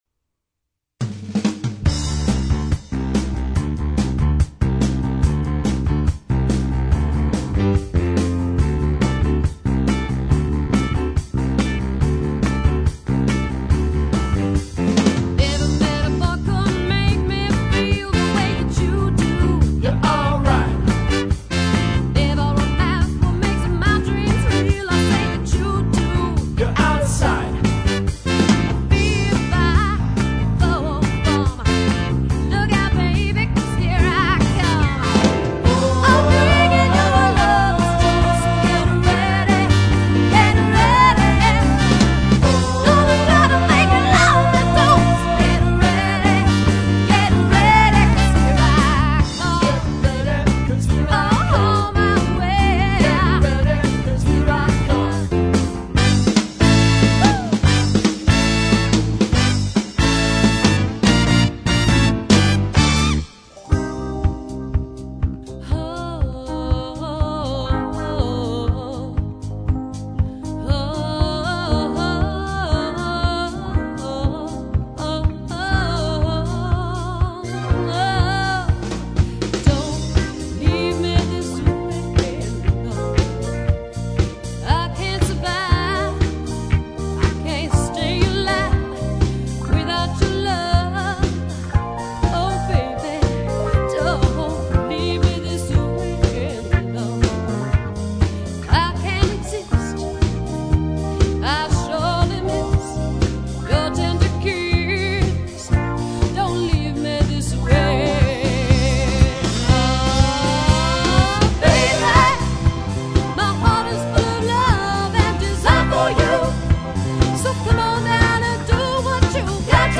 classic Soul, Rhythm & Blues, and Funk